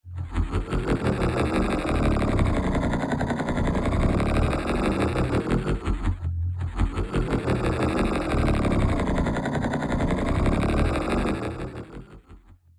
Evil Laught 02 HR